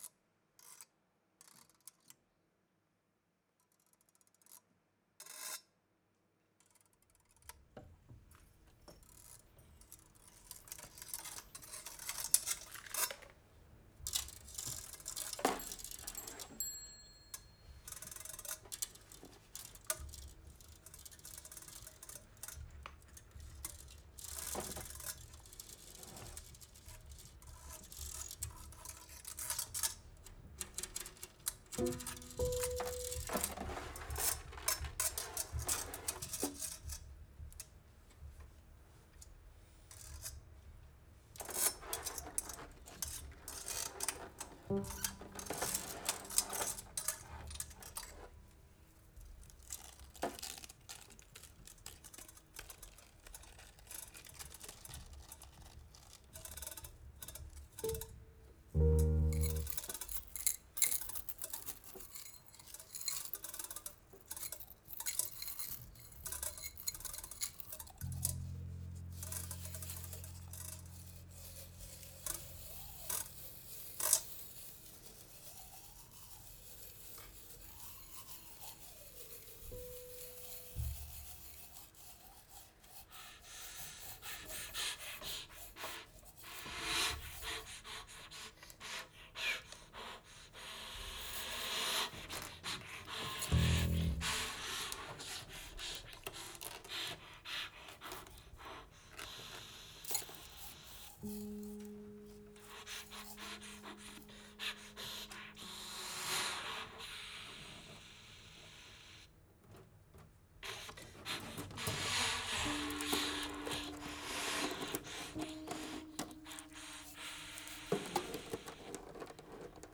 Extraordinary, free, and expressive